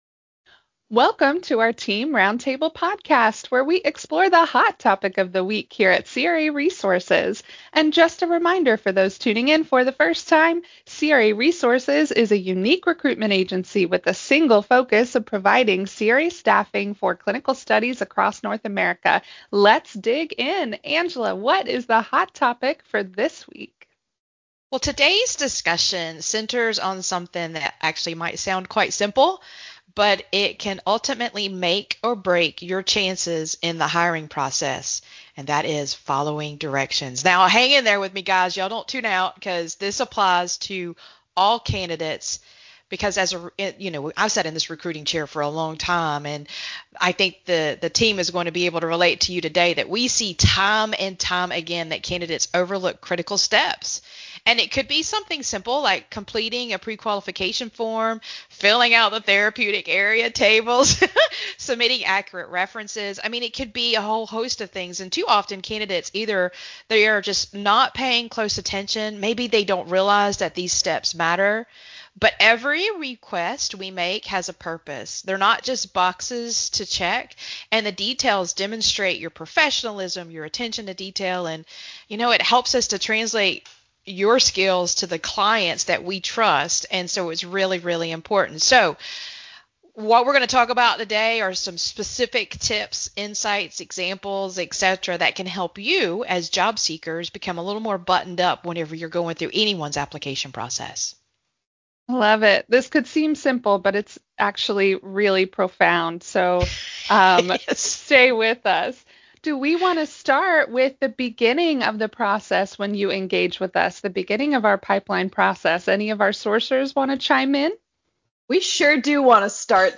Roundtable: An Edge in Job Application Success - craresources